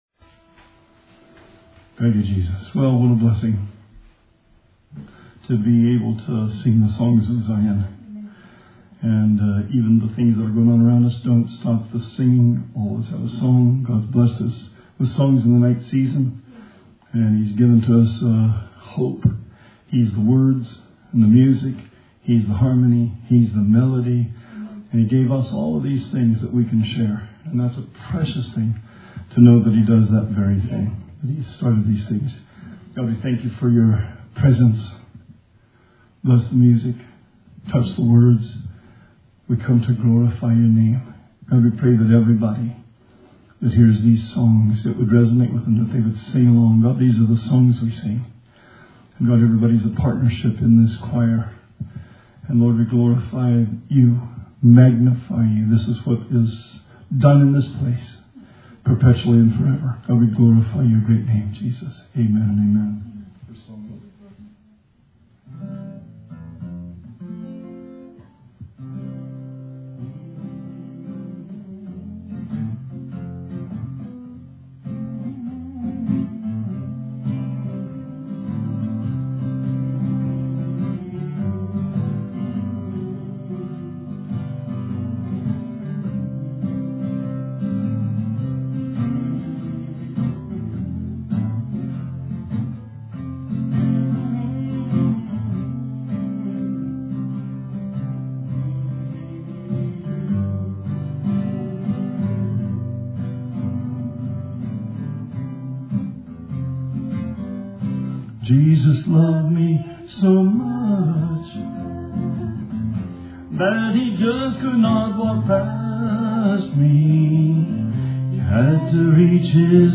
Bible Study 3/25/20